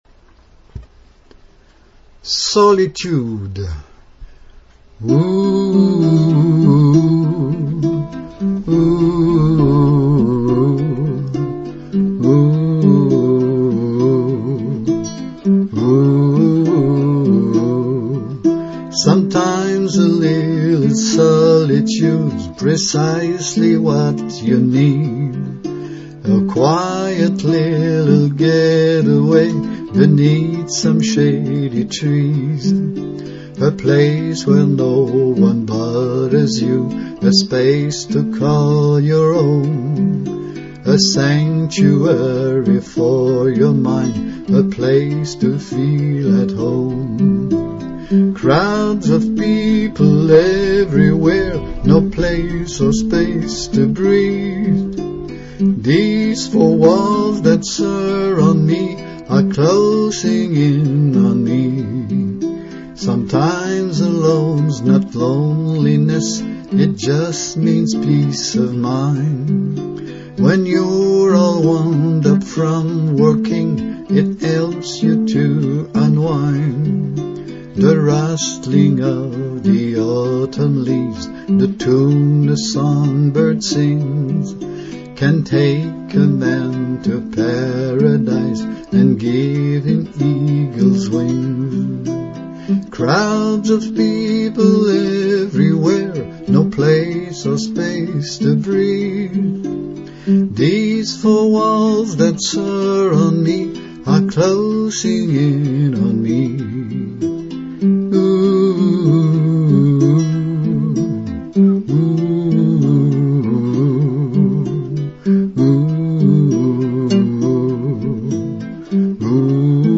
Rough demo: my voice + ukulele Solitude
Intro: 8 bars (key of C) Sometimes a little solitude's Precisely what you need.